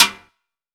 Index of /90_sSampleCDs/AKAI S6000 CD-ROM - Volume 5/Cuba2/TIMBALES_2